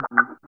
23 WAH    -L.wav